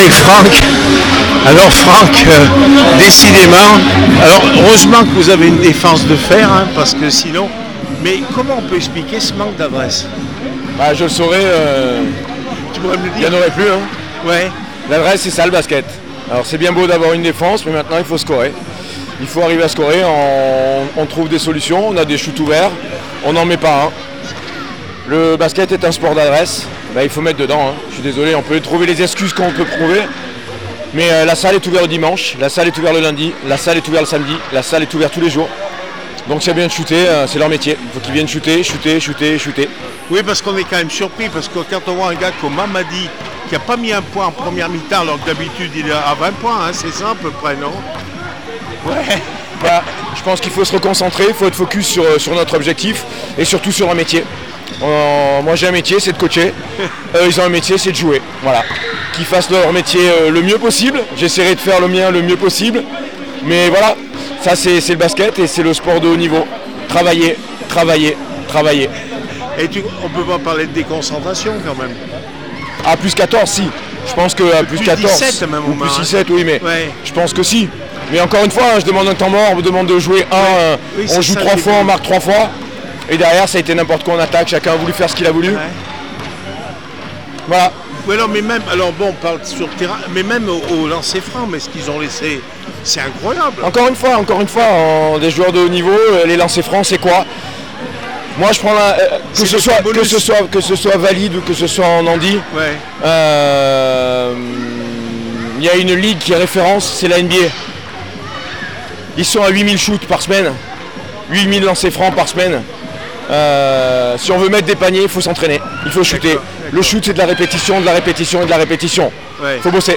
handibasket Elite nationale les aigles du Velay 66-hyeres 59 réaction après match